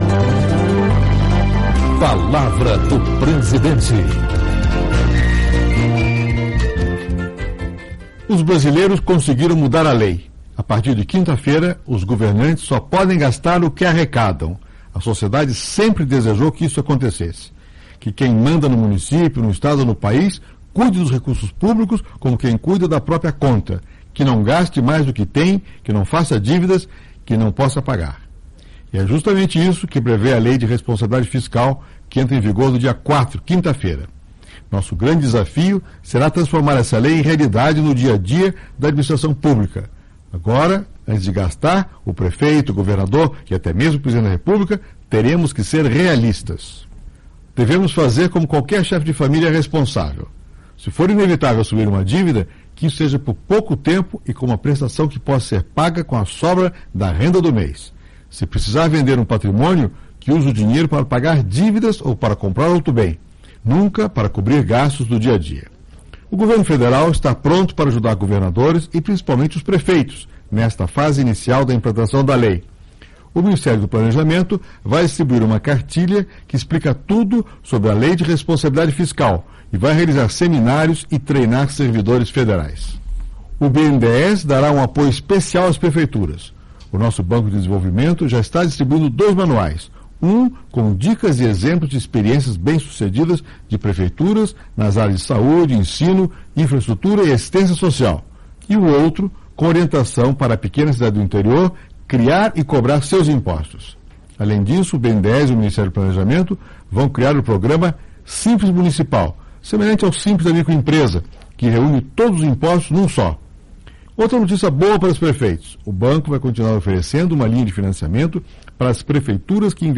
Escute também o discurso do presidente FHC sobre a Lei de Responsabilidade Fiscal: